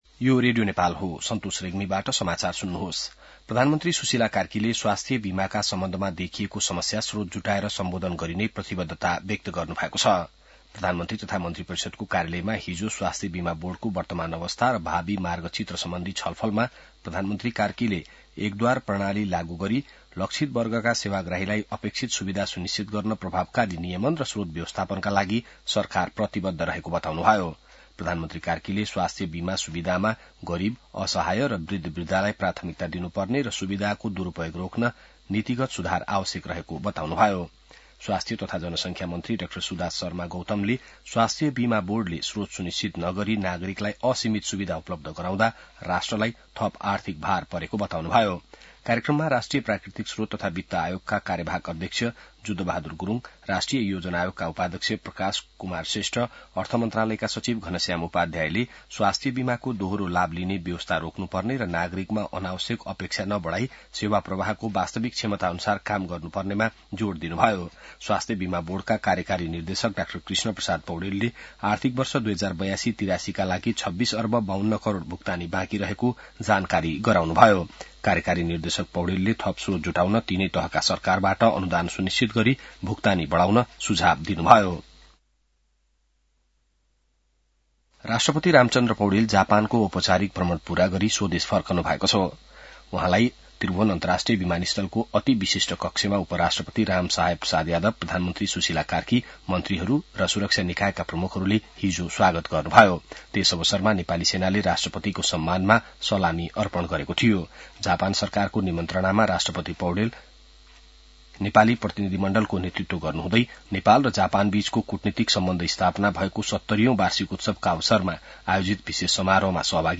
बिहान ६ बजेको नेपाली समाचार : २२ माघ , २०८२